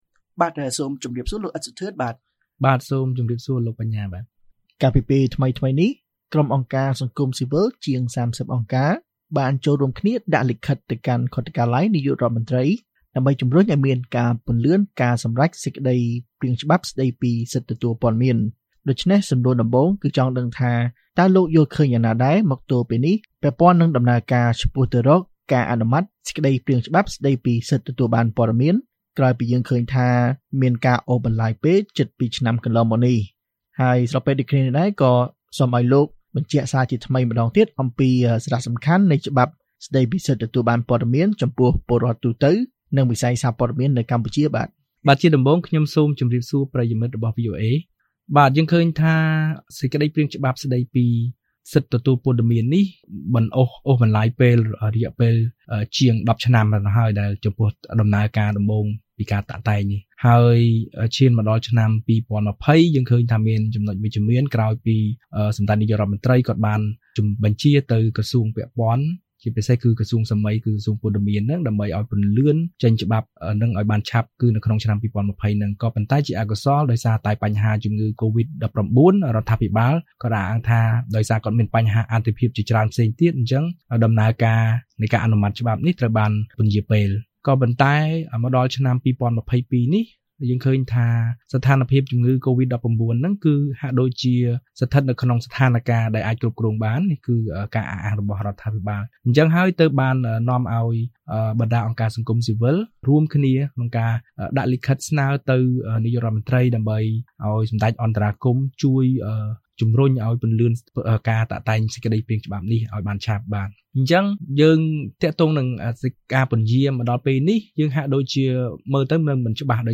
បទសម្ភាសន៍ VOA៖ ច្បាប់ស្តីពីសិទ្ធិទទួលព័ត៌មានមានសារៈសំខាន់សម្រាប់ពលរដ្ឋ និងវិស័យសារព័ត៌មាន